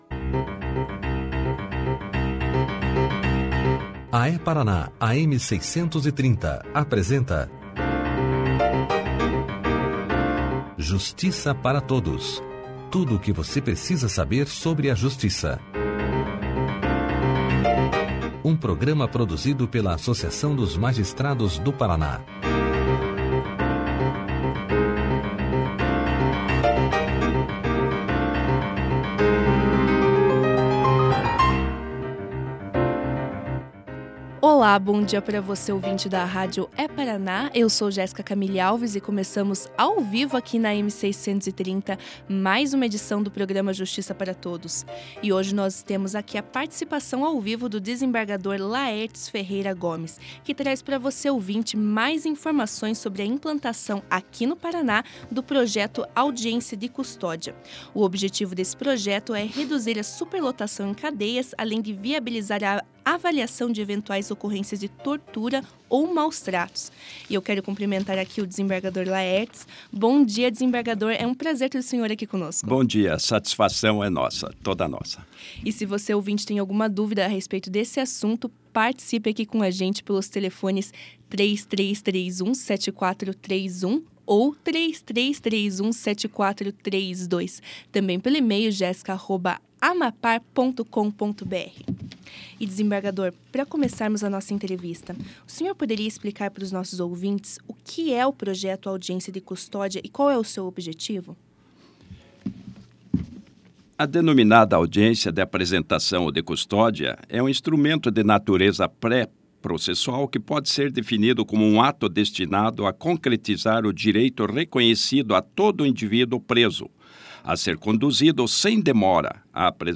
O desembargador Laertes Ferreira Gomes falou nessa segunda-feira (14), aos ouvintes da rádio É-Paraná sobre a implantação no estado do Paraná do Projeto Audiência de Custódia, que visa garantir que presos em flagrante sejam apresentados a um juiz num prazo máximo de 24horas. O objetivo do projeto é reduzir a superlotação em cadeias, além de viabilizar a avaliação de eventuais ocorrências de tortura ou maus-tratos.
Clique aqui e ouça a entrevista do desembargador Laertes Ferreira Gomes sobre o Projeto Audiências de Custódia na íntegra.